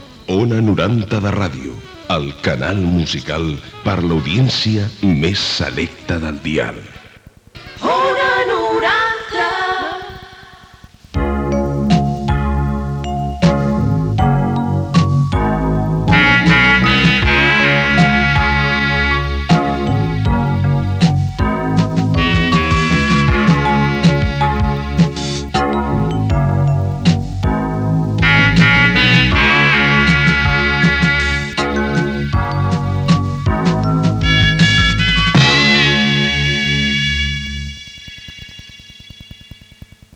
Identificació, indicatiu i sintonia de l'emissora.